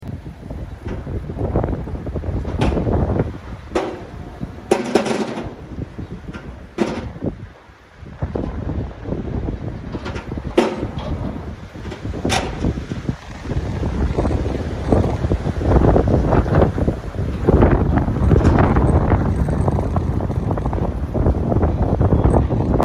19.02.22: Ein schwerer Sturm reißt den Bauzaun ein drittes Mal um und zerlegt diesmal an der Westflanke das Gerüsts - hier ein nächtliches Video sowie die
Akustik dazu.
Bauzaunumfaller Nr. 3 und Gerüstschäden
• GERÜSTKLAPPERN: Ab Windstärke 5-6 rappeln die Bauteile des Gerüst vor sich hin, inbesondere auch gerne nachts.